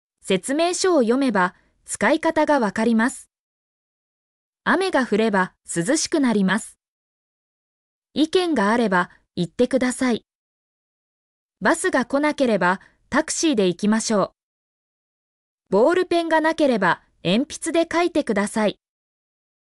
mp3-output-ttsfreedotcom-14_Q5ZwHmrK.mp3